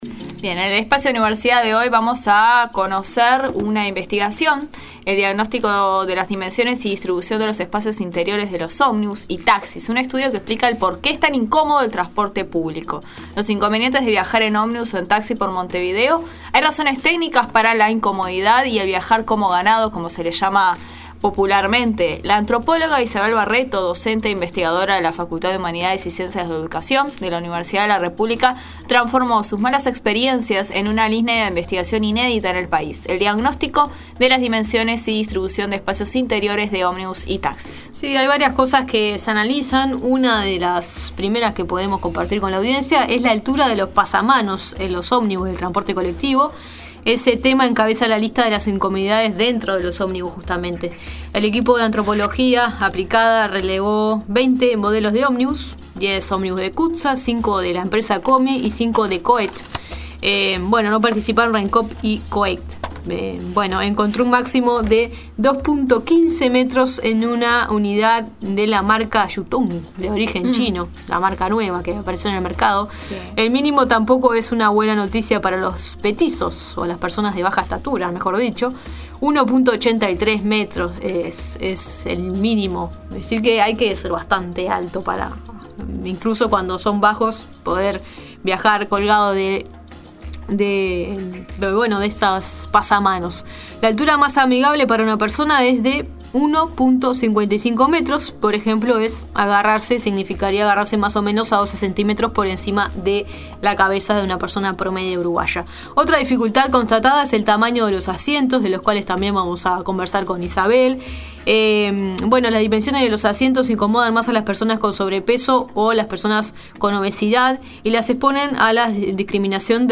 La Nueva Mañana conversó